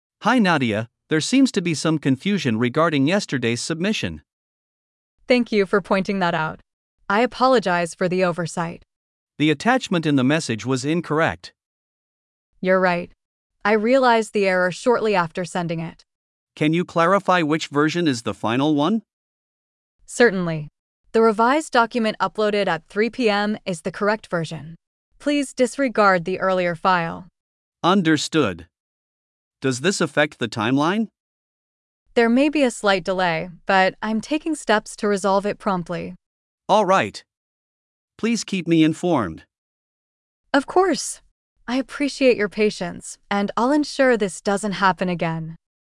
🤝 A manager addresses a mistake in a work submission.